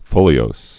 (fōlē-ōs)